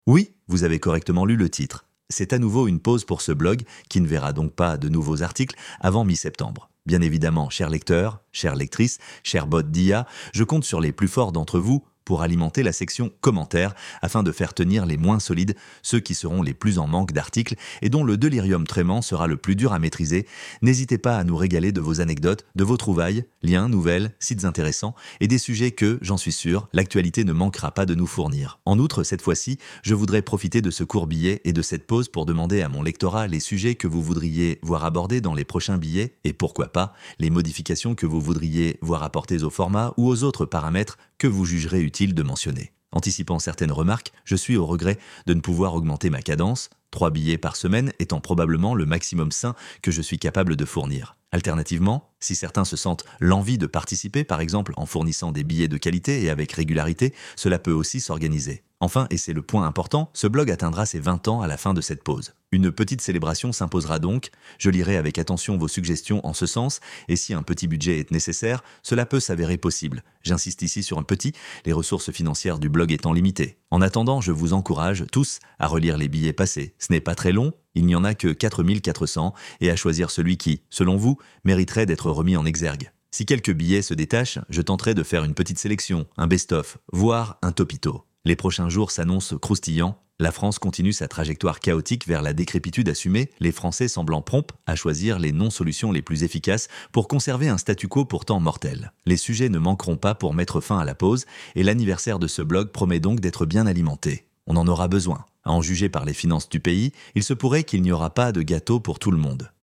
Une narration automatique est possible mais le résultat est généralement décevant.
Cela manque d’emphase et de jeu à plusieurs endroits, ça rend le texte un peu plat 🙁
nouvelle-pause-estivale-voix.mp3